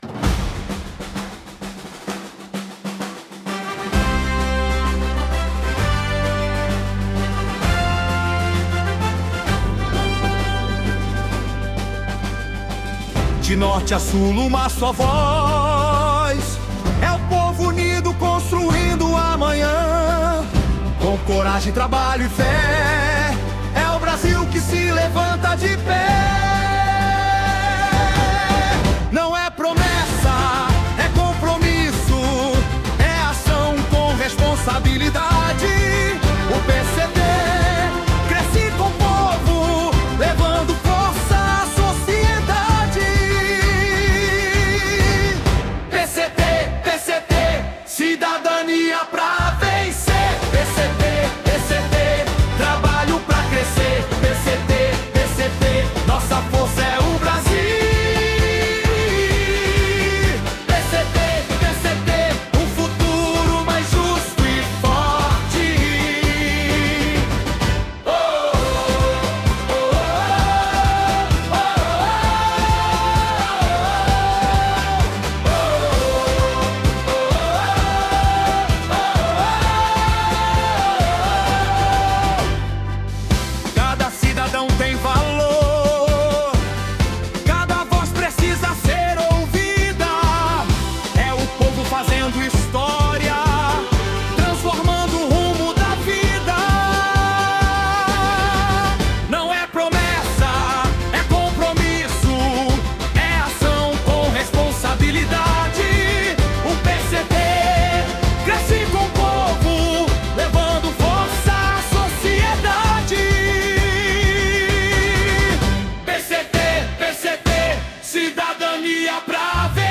Conheça o Hino do PCT
hino-pct.mp3